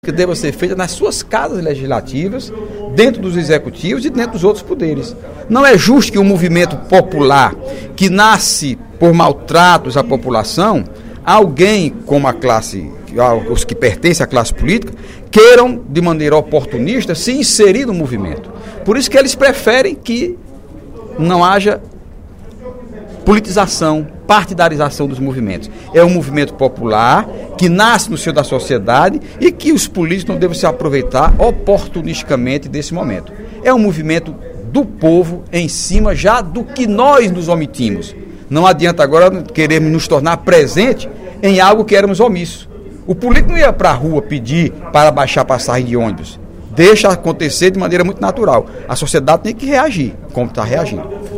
Durante o primeiro expediente desta terça-feira (18/06), o deputado Heitor Férrer (PDT) fez uma reflexão sobre as recentes manifestações que tomaram conta de várias capitais, em todo o país, e que teve início com as reivindicações dos paulistanos para que o preço da passagem de ônibus em São Paulo não fosse reajustado.